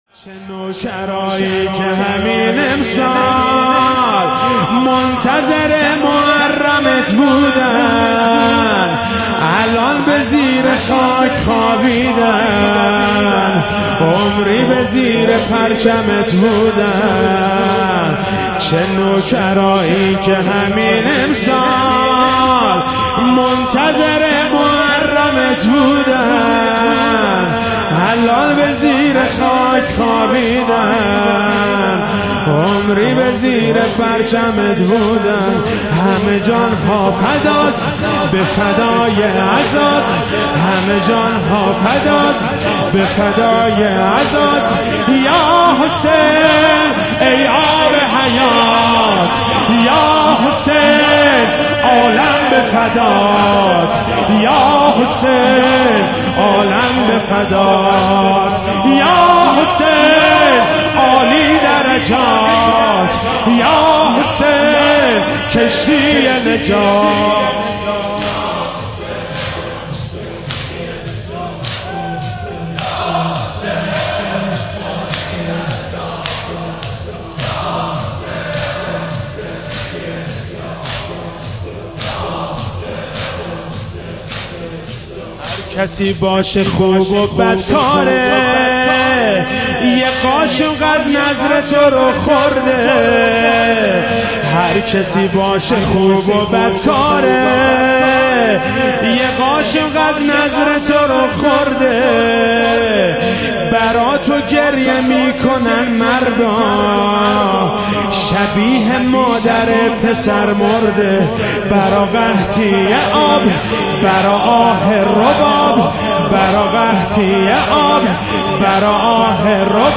مداحی جدید
شب دوم محرم97